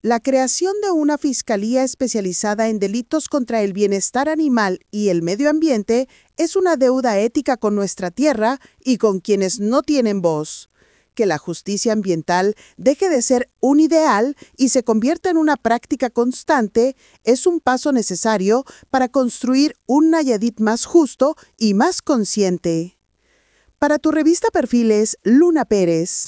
🎙 COMENTARIO EDITORIAL: